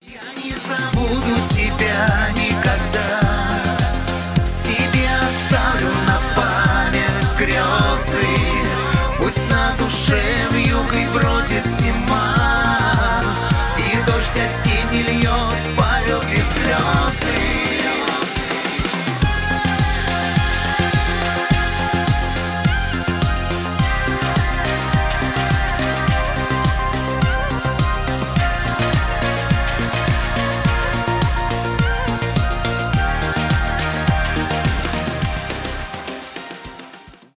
шансон